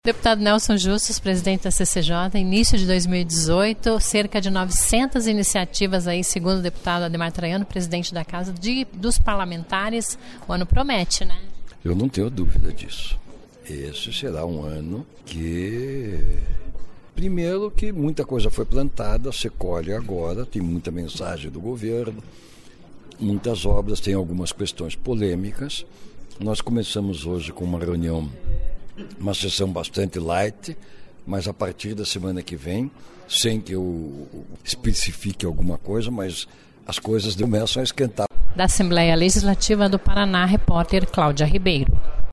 Ouça entrevista com o rpesidente da CCJ, deputado Nélson Justus (DEM).